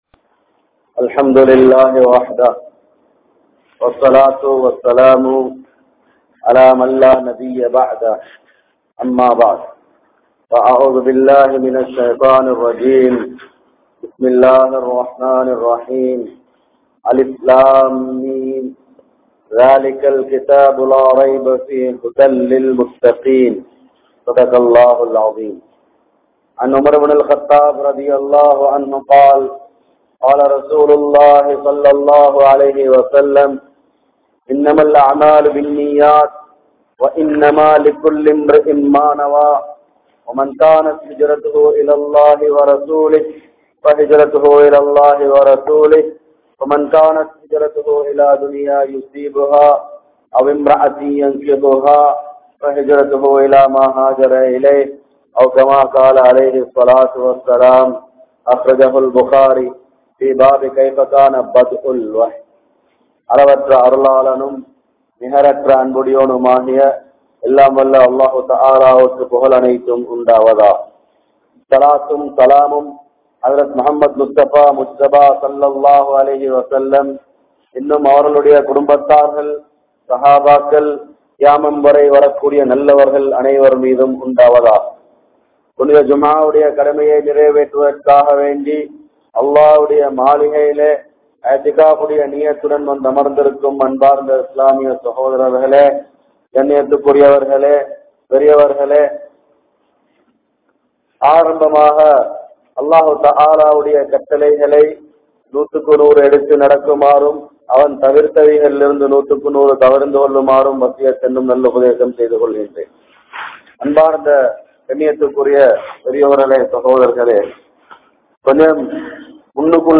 Kulanthai Valarpu (குழந்தை வளர்ப்பு) | Audio Bayans | All Ceylon Muslim Youth Community | Addalaichenai